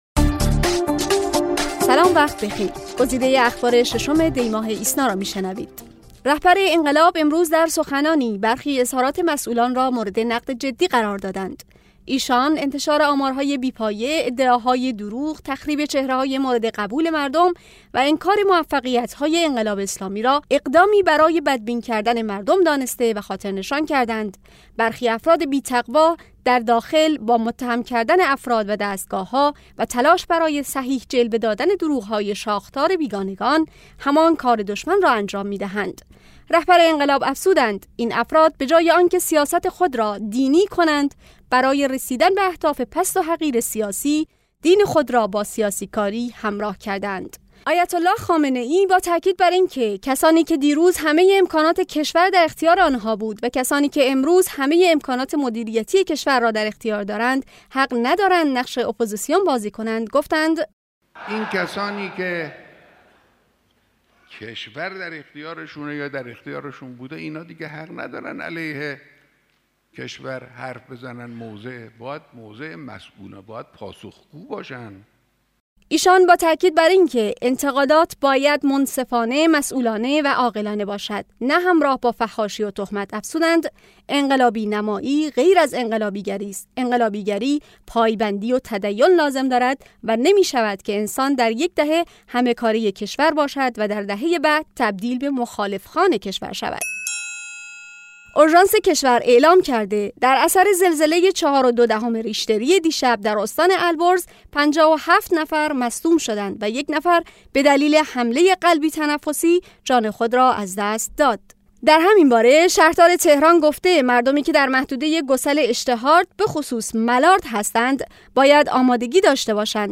صوت / بسته خبری ۶ دی ۹۶